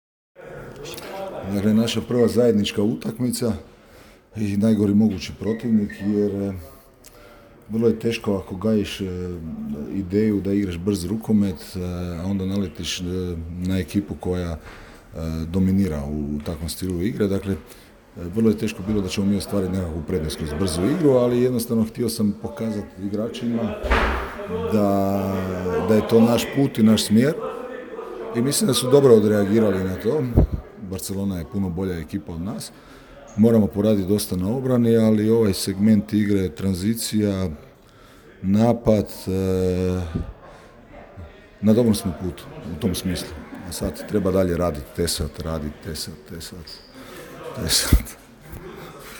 “Ovo je bio najgori mogući protivnik i teško je, ako gajiš ideju da igraš brzi rukomet, onda naletiš na momčad koja dominira u takvom stilu igre,” između ostalog je rekao Šola nakon potopa pa naglasio kako misli da su na dobrom putu…